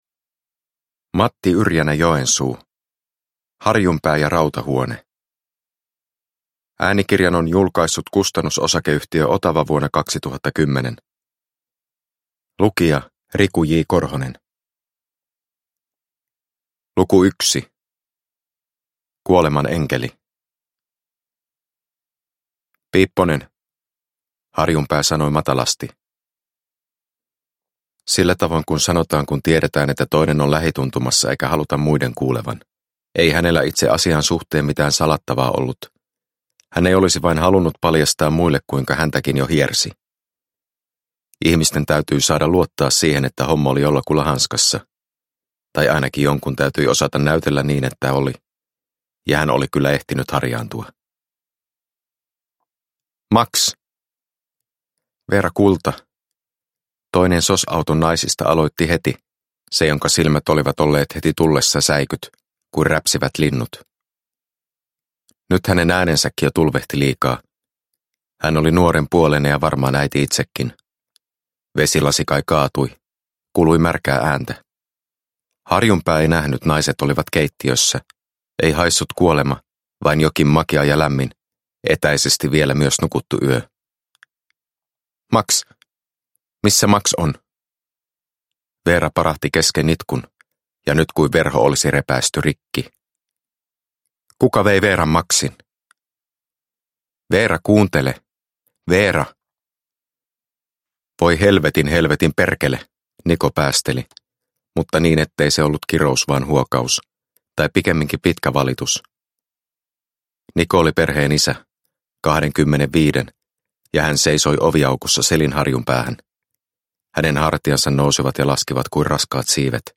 Harjunpää ja rautahuone – Ljudbok – Laddas ner